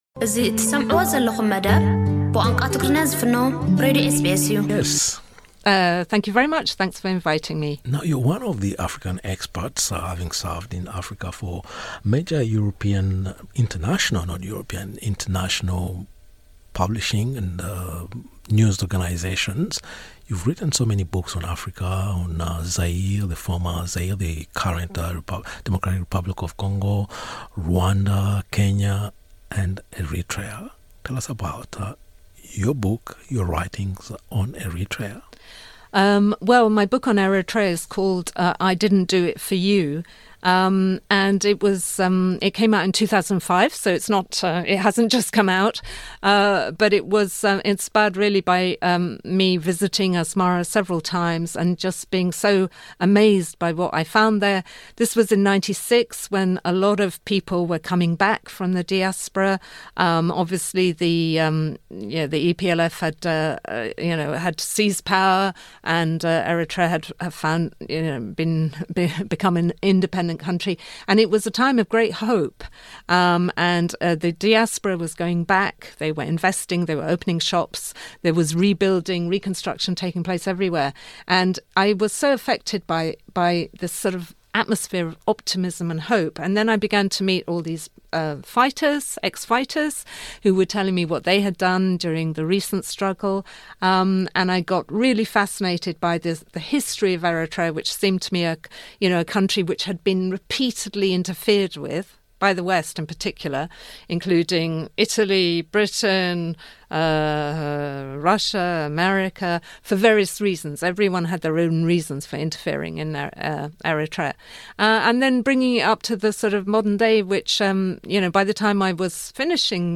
During her trip to Australia, Michela Wrong, a respected author known for her perceptive analyses of African countries such as Eritrea and Rwanda, engaged in a discussion with SBS Tigrinya. She illuminated the themes explored in her book "I Didn't Do It for You," particularly delving into the complex political environment of Eritrea. Additionally, she highlighted the burgeoning presence of young Eritrean movements dedicated to instigating transformation within the nation.